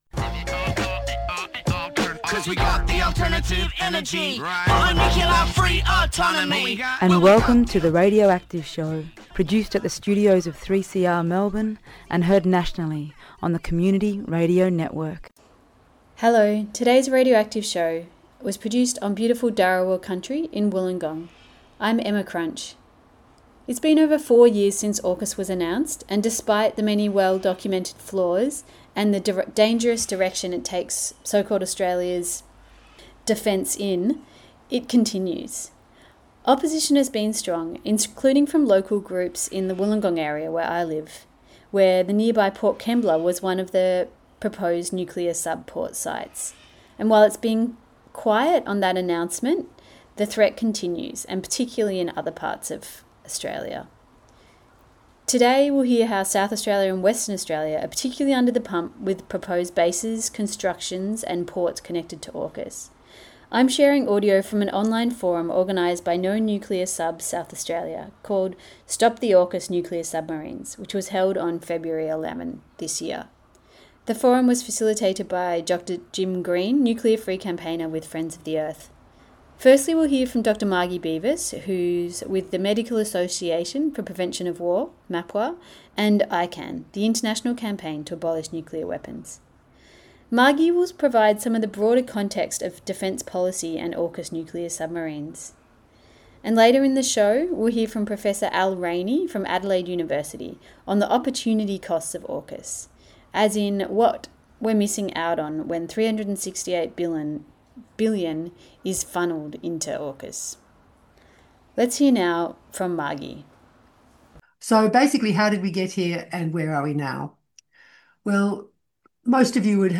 Sharing audio from an online forum organised by no nuclear subs SA, ‘Stop the AUKUS nuclear submarines’, held on February 11.